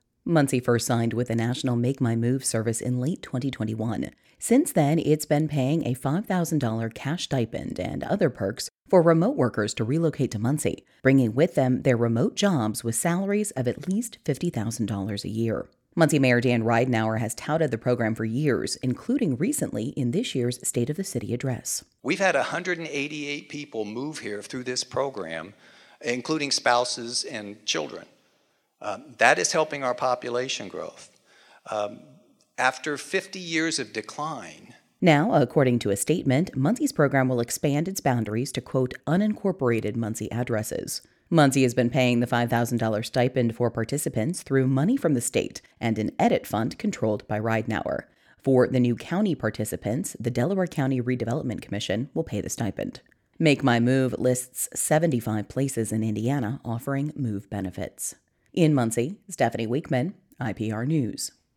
IPR News